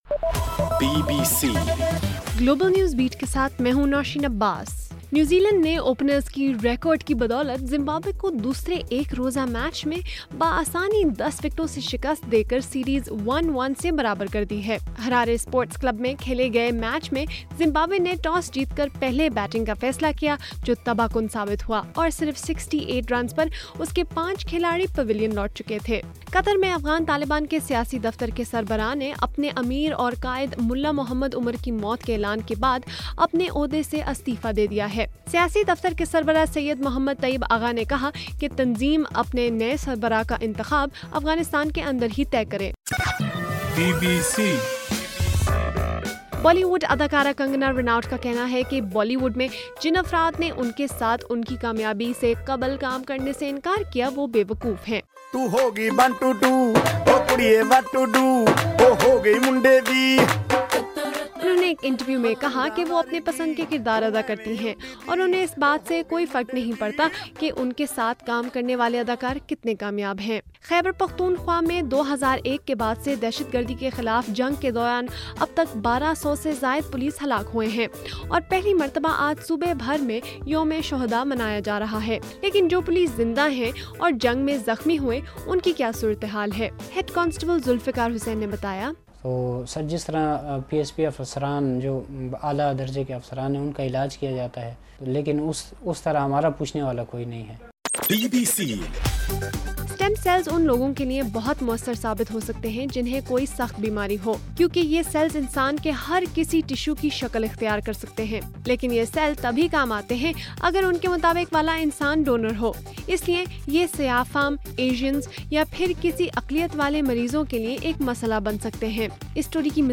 اگست 4: رات 9 بجے کا گلوبل نیوز بیٹ بُلیٹن